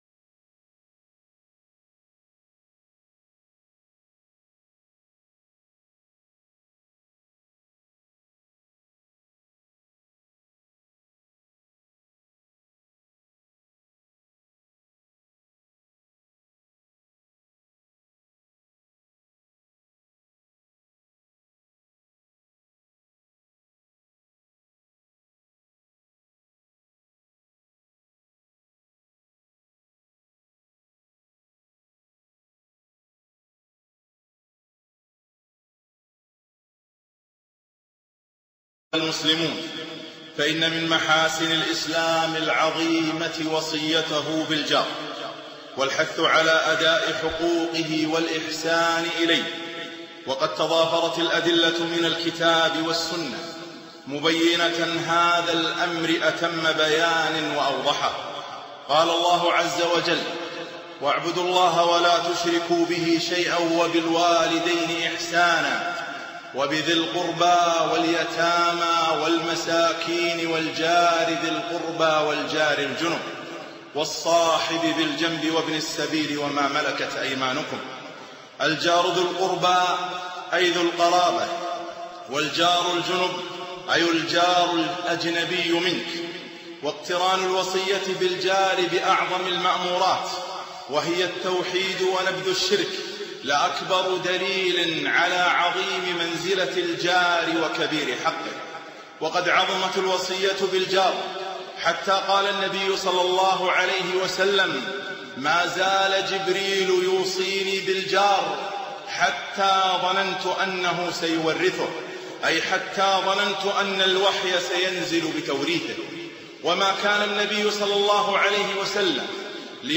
خطبة - حسن الجوار